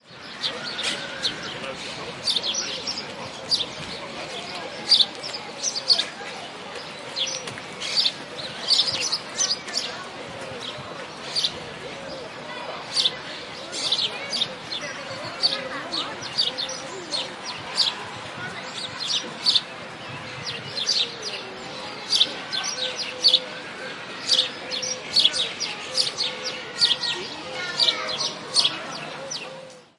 描述：在繁忙的高速公路立交桥下录制的录音。在立交桥下面是许多鸟类栖息的溪流。
Tag: 道路 鸟鸣 公路 交通 汽车 现场记录 立交桥